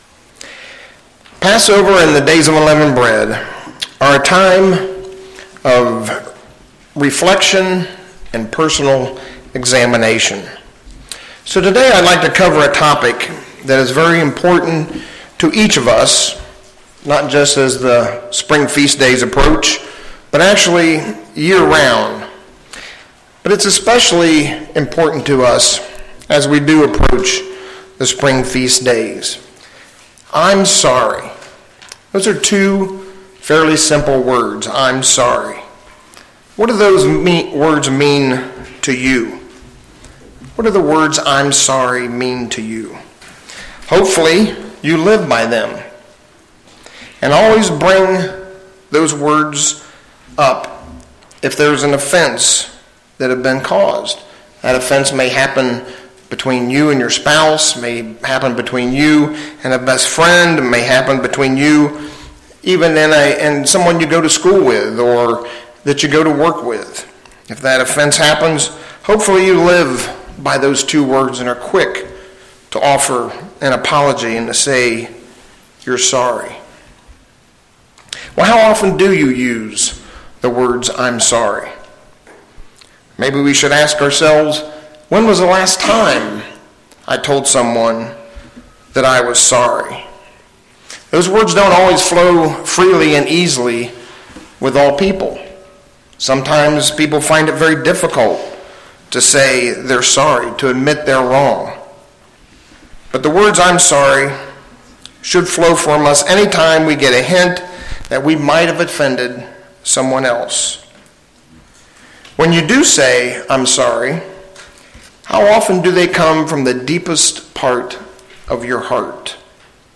This sermon goes through a path to heartfelt repentance.